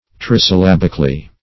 -- Tris`yllab"ic*al*ly , adv.
trisyllabically.mp3